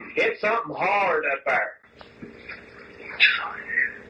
EVP-3___